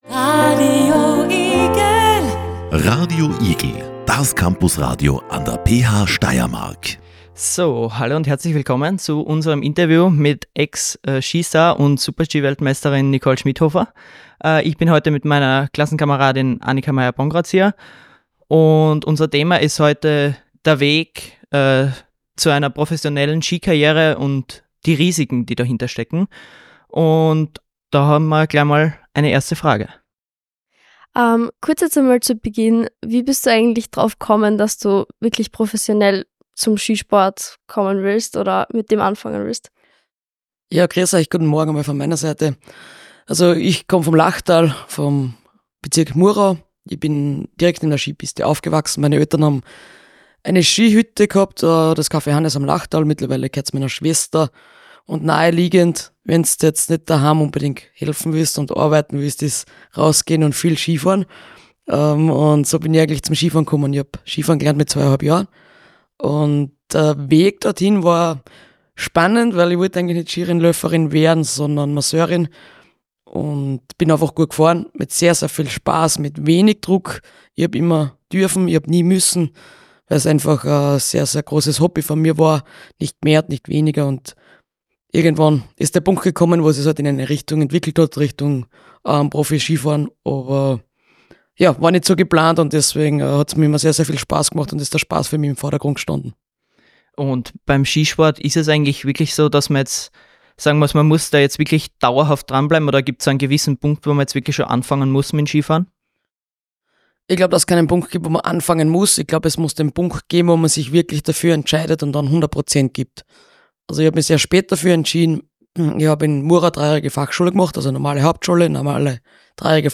Nicole Schmidhofer im Gespräch